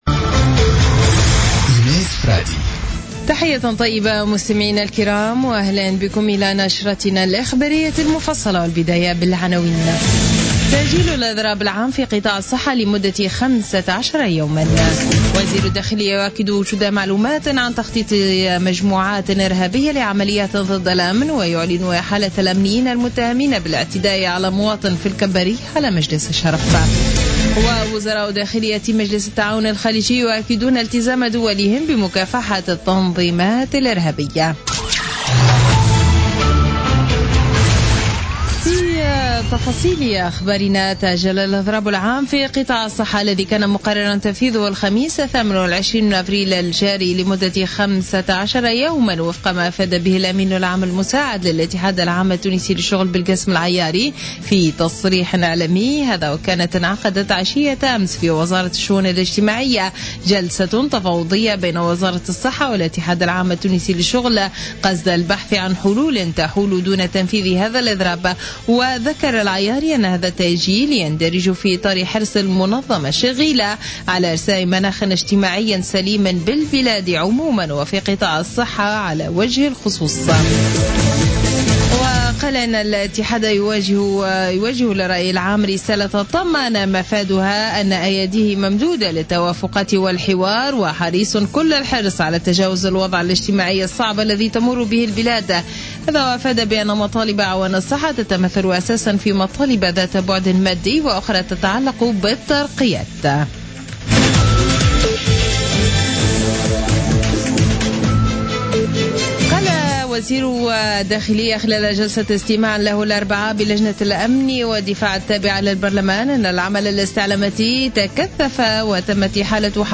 نشرة أخبار منتصف الليل ليوم الخميس 28 أفريل 2016